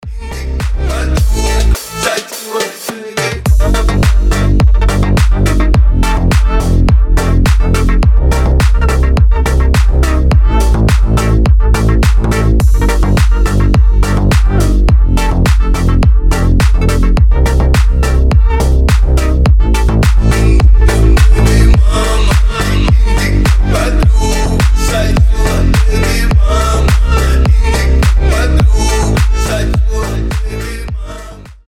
• Качество: 320, Stereo
deep house
мощные басы
качающие
G-House
ремиксы